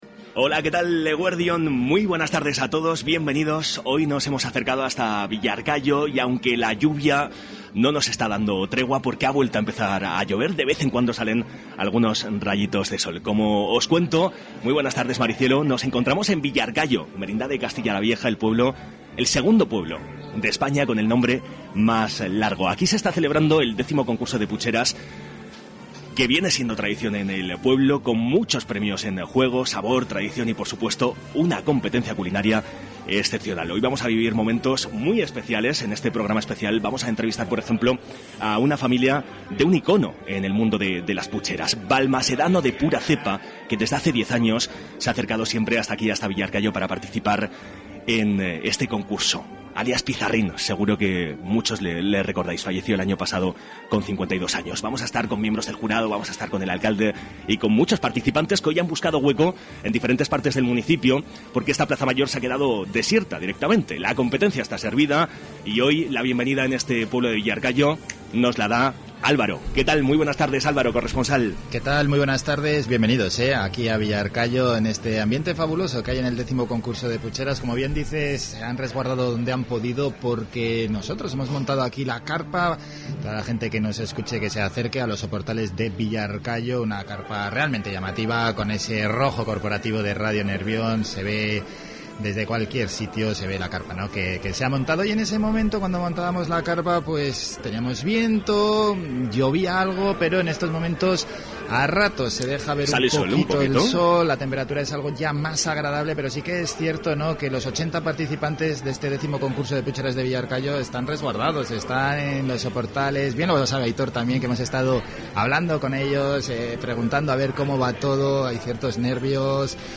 Escucha aquí, el programa especial de Radio Nervión desde el X Concurso de Pucheras de Villarcayo